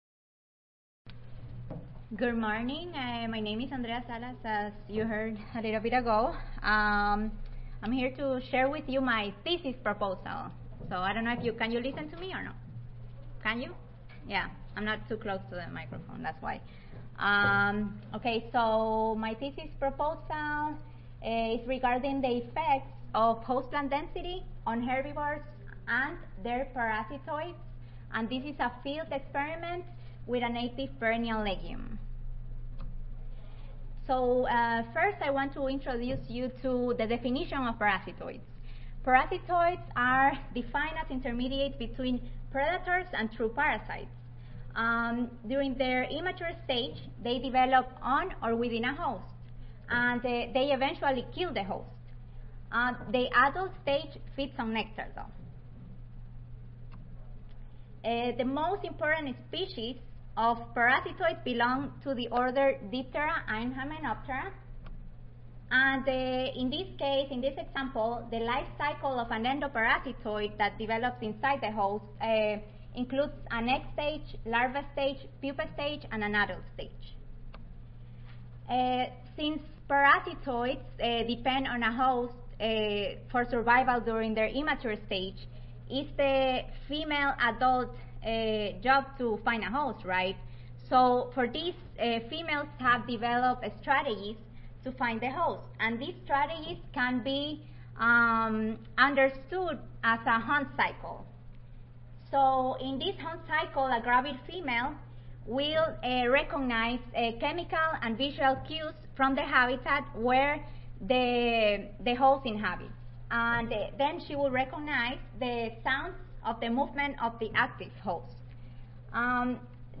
Florida International University Audio File Recorded Presentation